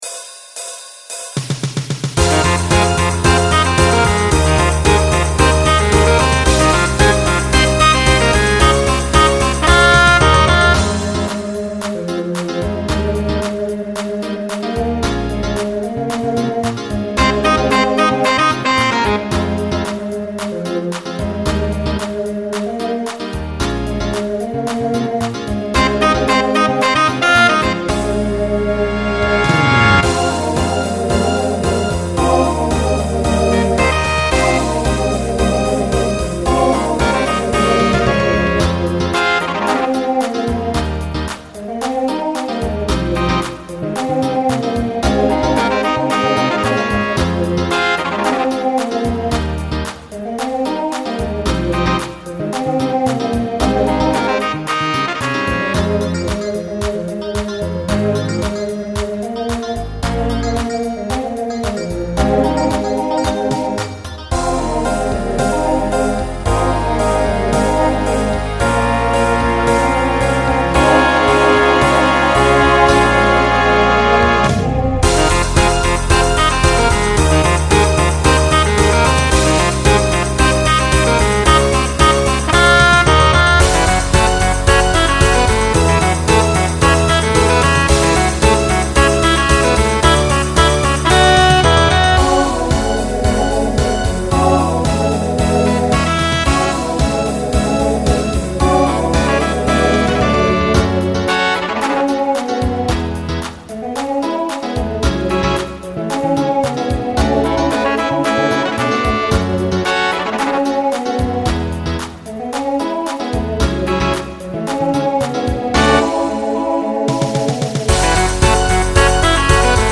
Voicing SATB Instrumental combo Genre Broadway/Film , Rock
Mid-tempo